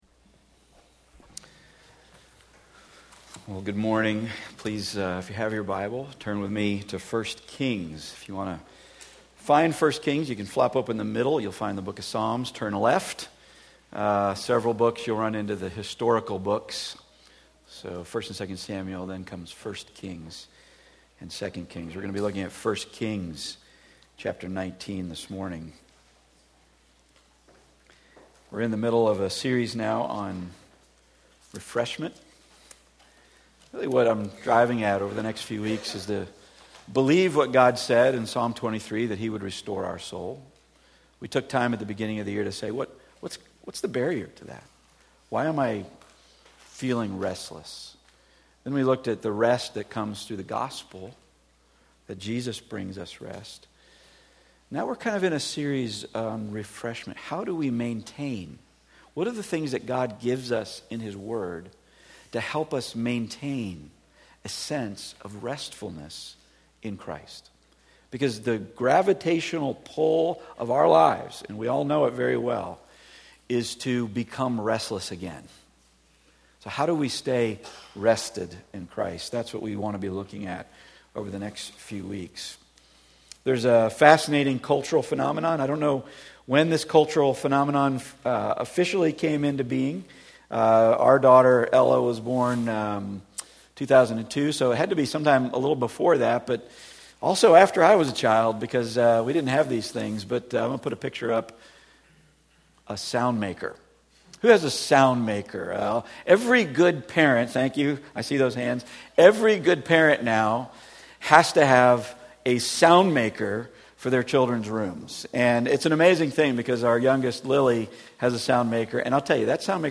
Micah 5:1-5 Service Type: Weekly Sunday